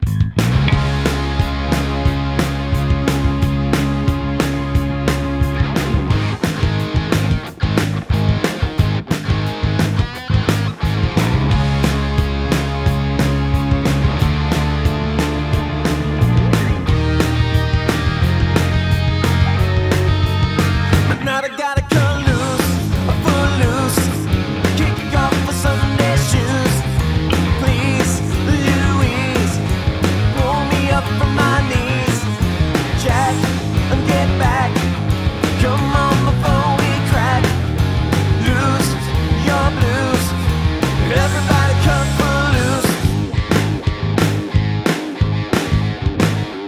Guitars seem very dry as well.